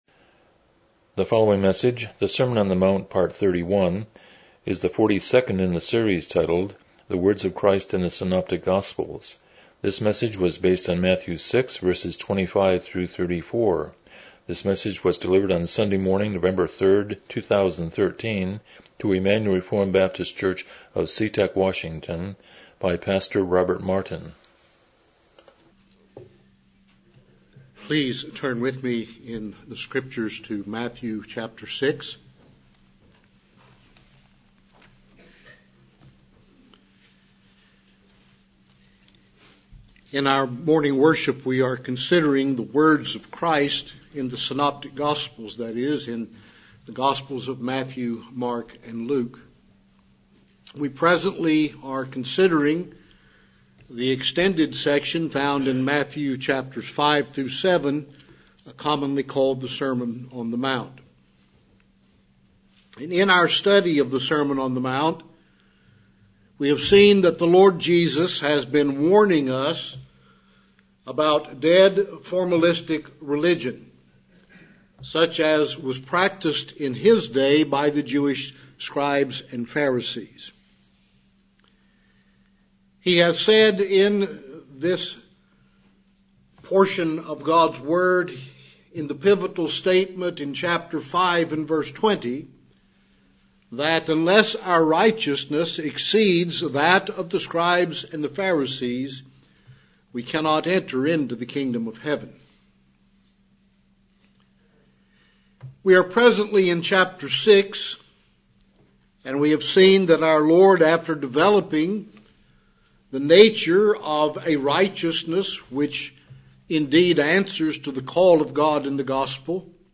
Passage: Matthew 6:25-34 Service Type: Morning Worship